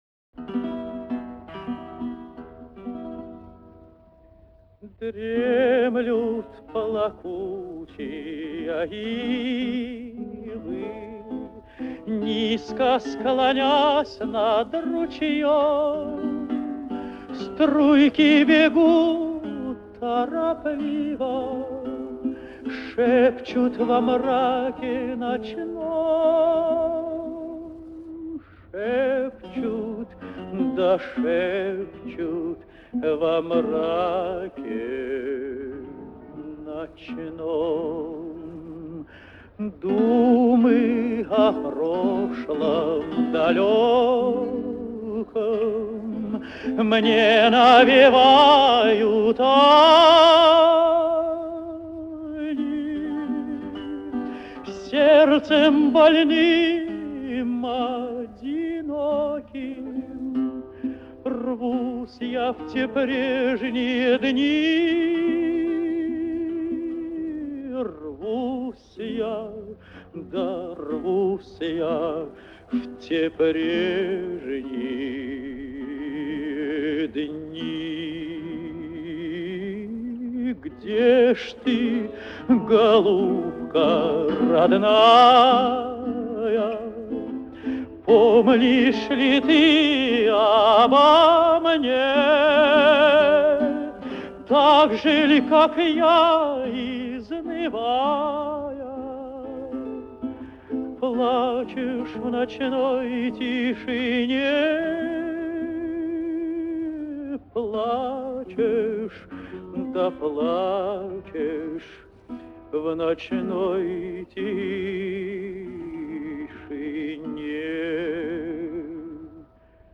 старинный русский романс